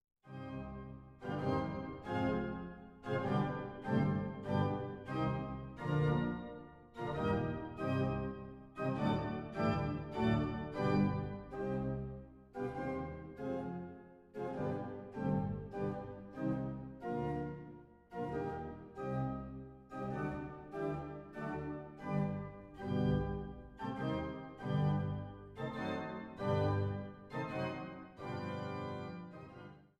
6 Aus "Skizzen für Pedalflügel" op. 58 - Nr. I, Nicht schnell und sehr markiert